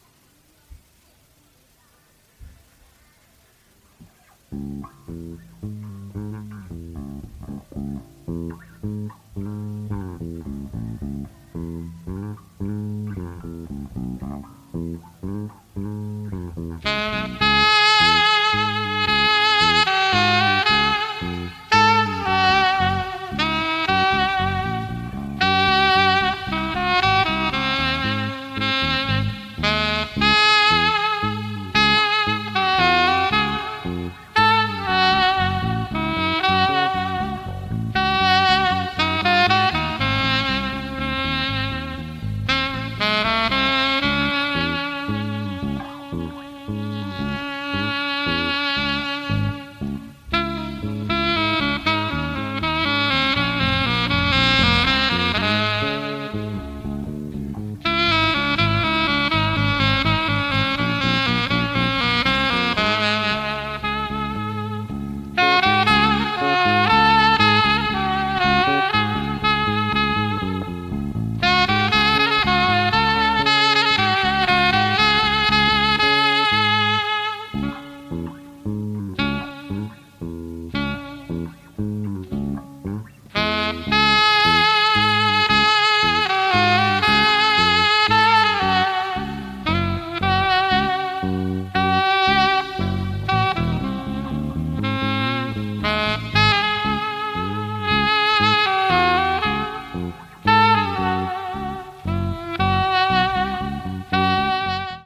群馬県前橋市の百貨店前にて録音された